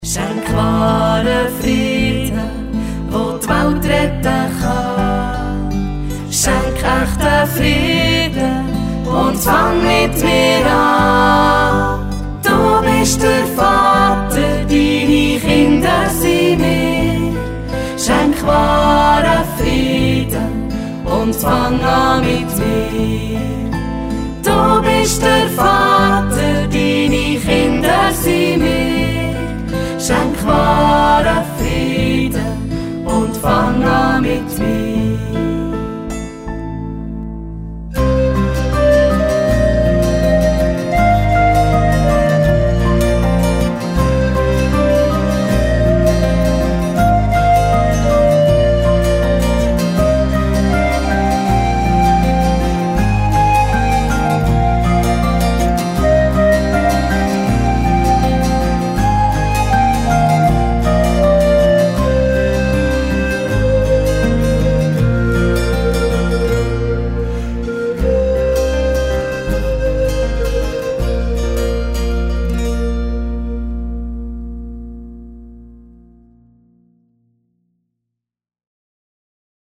Swiss version